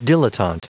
Prononciation du mot dilettante en anglais (fichier audio)
Prononciation du mot : dilettante